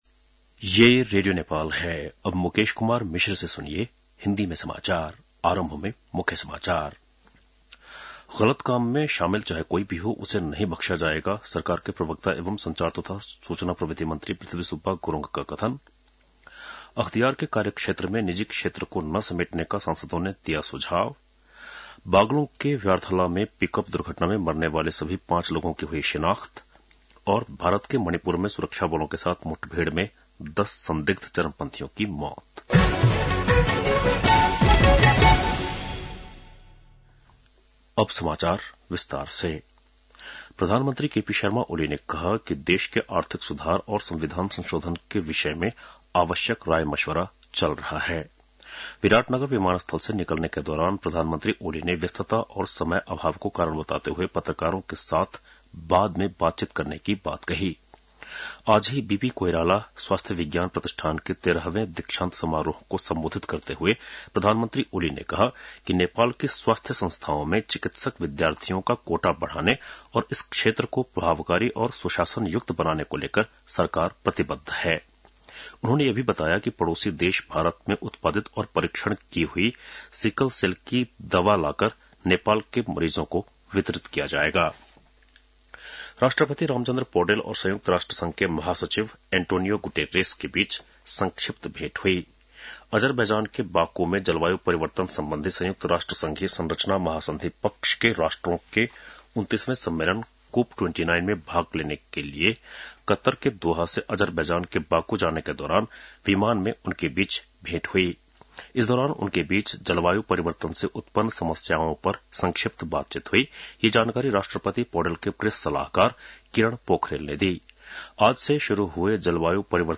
बेलुकी १० बजेको हिन्दी समाचार : २७ कार्तिक , २०८१